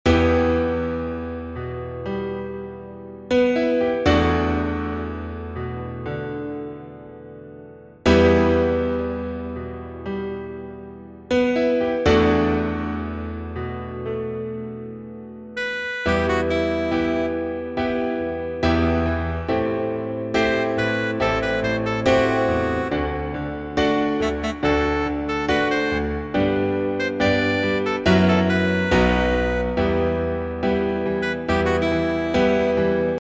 Tempo: 120 BPM.
MP3 with melody DEMO 30s (0.5 MB)zdarma